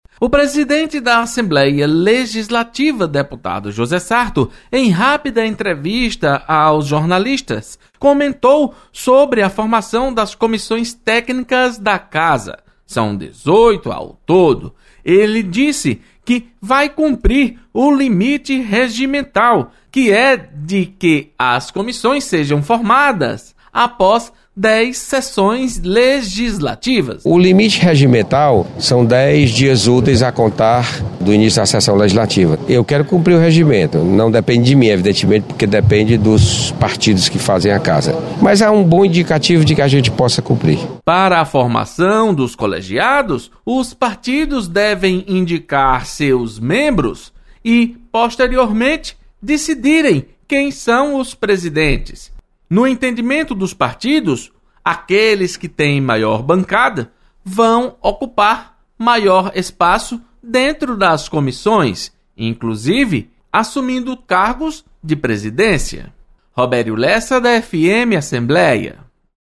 Presidente da Assembleia, deputado José Sarto ressalta cumprimento do prazo regimental para formação das comissões técnicas.